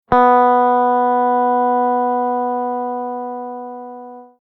Simply click the play button to get the sound of the note for each string (E, A, D, G, B and E).
B String
b-note.mp3